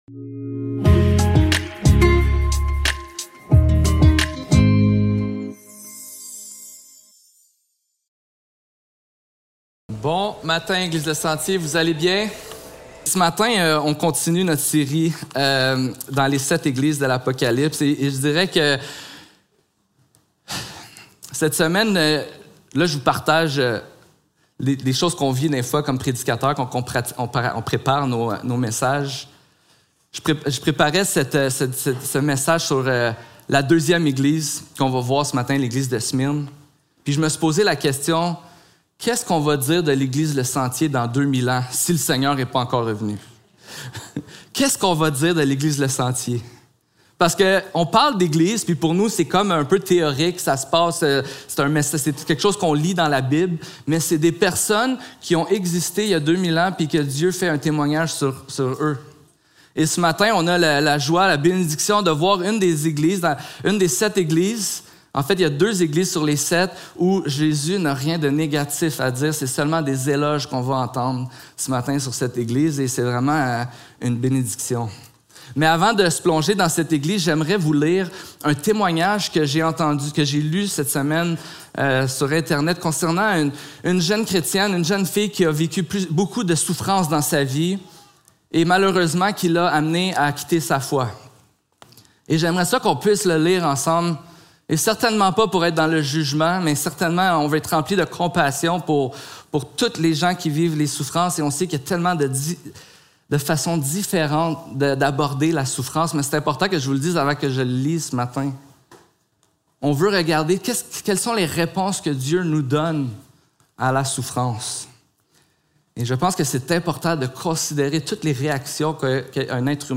Apocalypse 2.8-11 Service Type: Célébration dimanche matin Description